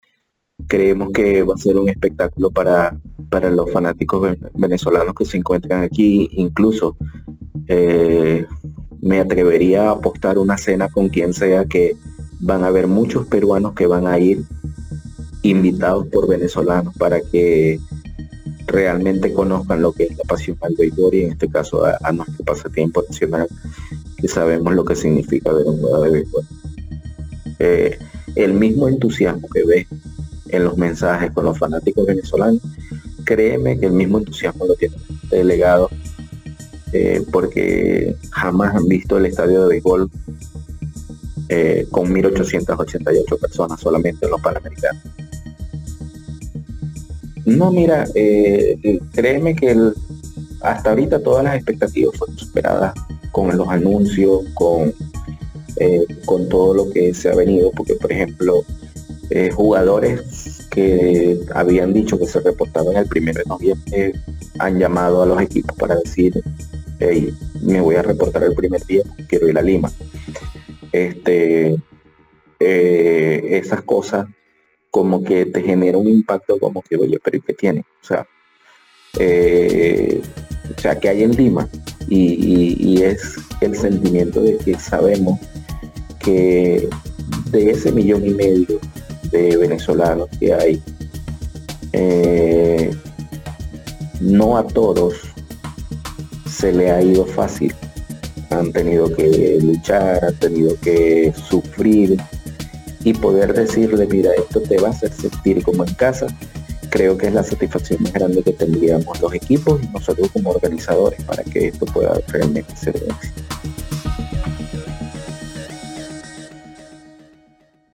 audio-entrevista-beisbol_01.mp3